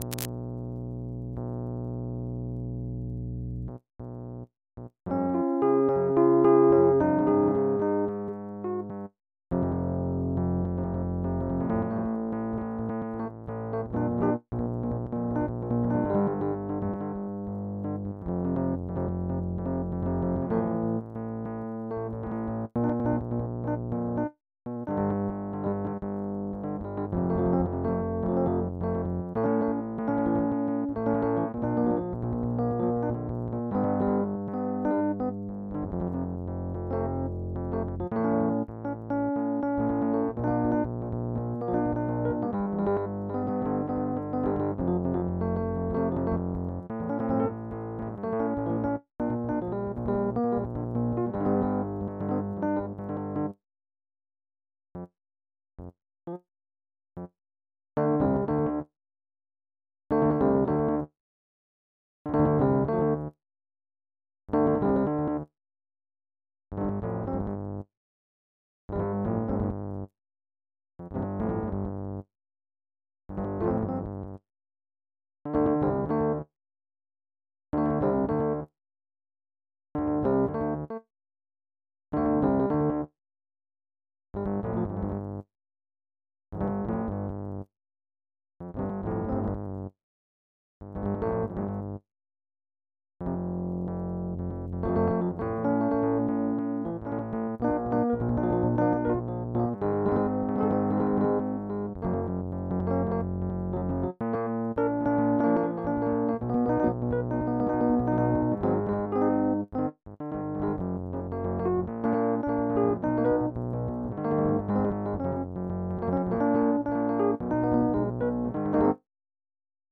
MIDI 8.81 KB MP3